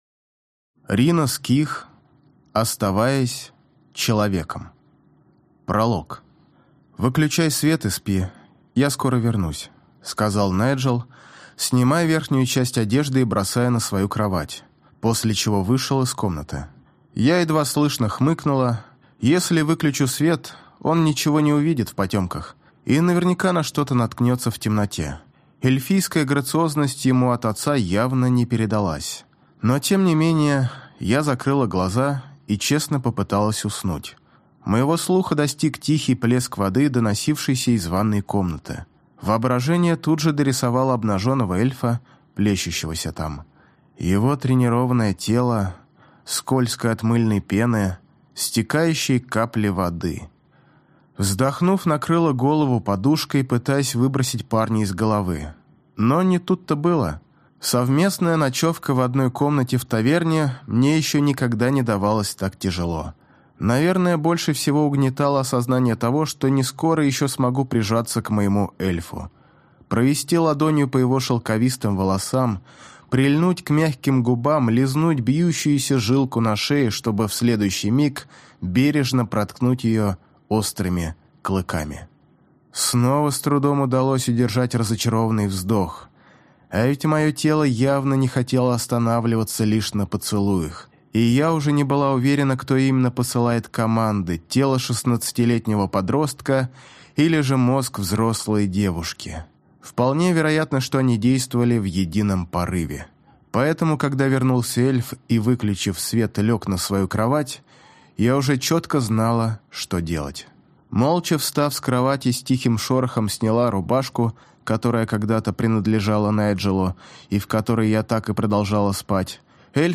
Аудиокнига Оставаясь человеком | Библиотека аудиокниг